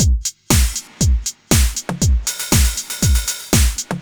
Index of /musicradar/retro-house-samples/Drum Loops
Beat 02 Full (120BPM).wav